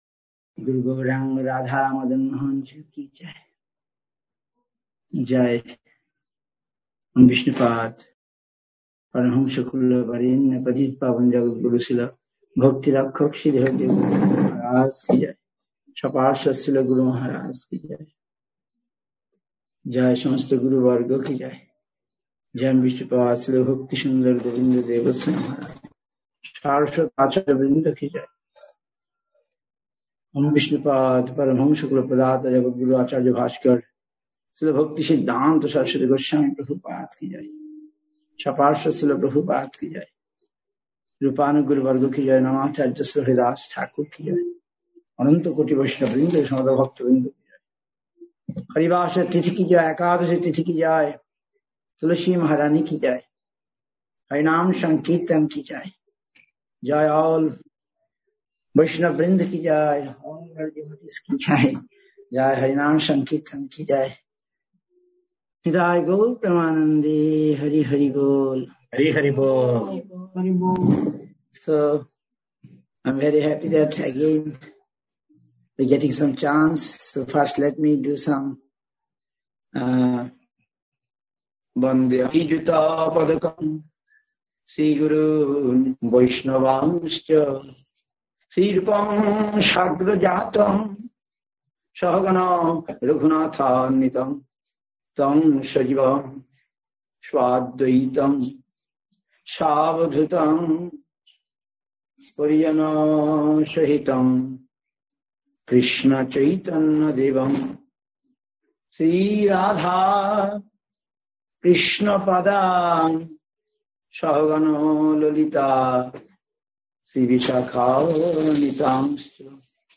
Harikhatha
India, Nabadwip, Kulia Gram, SREE CAITANYA SRIDHAR SEVA ASHRAM.